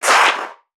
NPC_Creatures_Vocalisations_Infected [108].wav